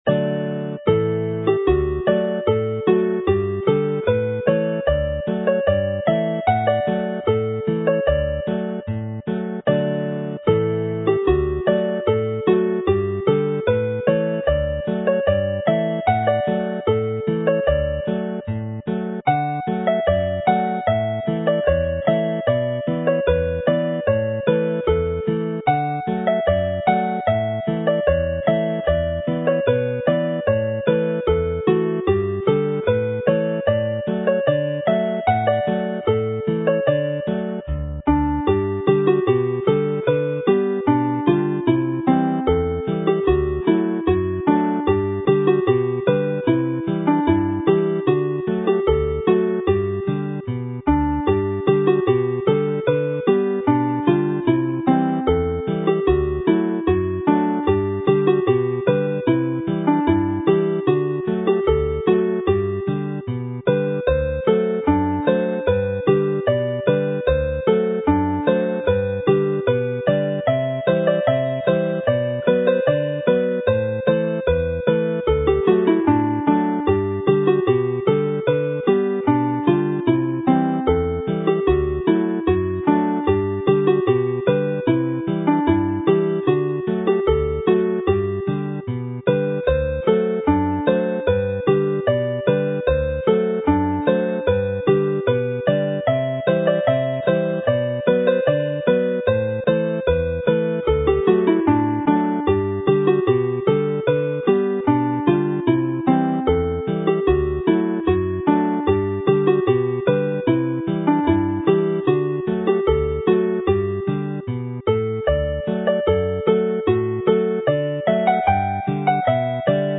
Play the set - once through each tune